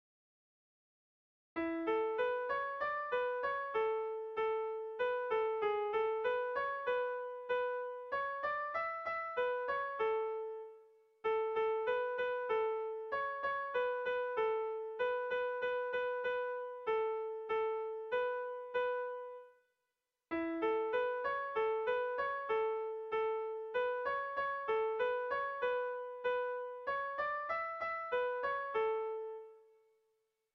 Erlijiozkoa
ABDE..